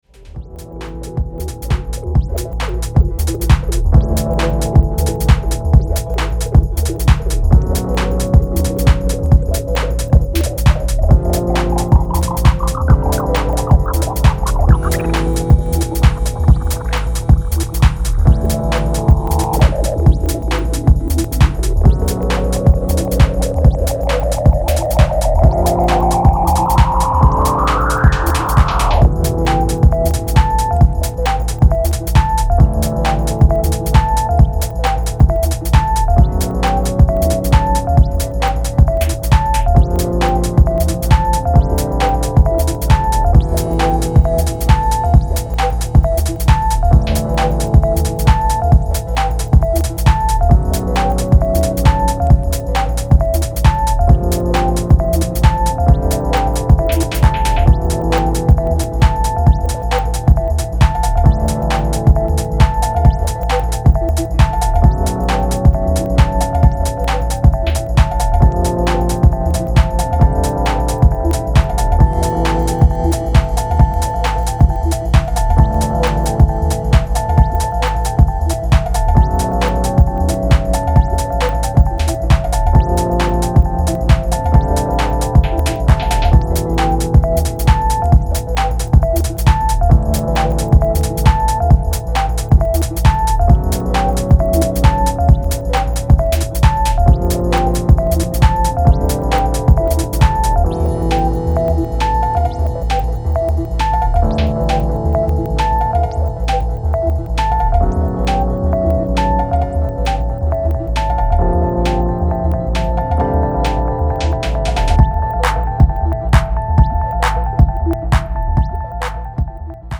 Reflective electro, Techno and beatless trips